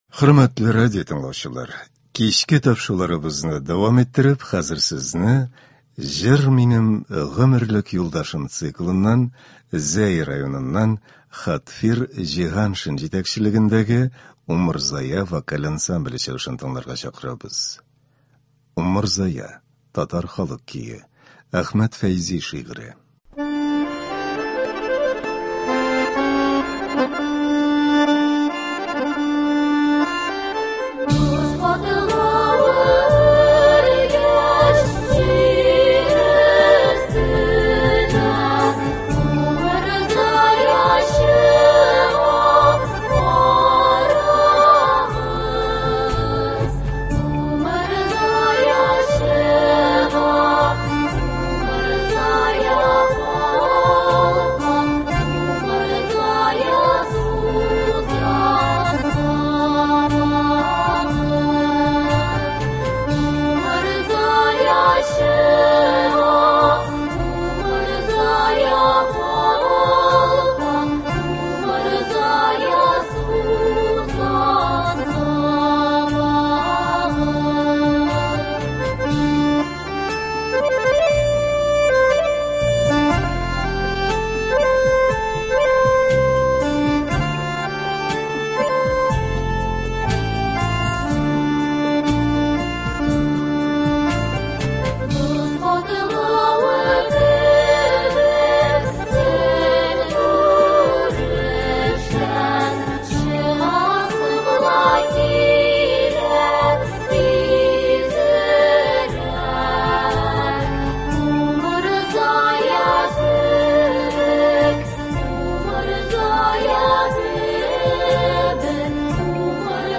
Музыкаль программа.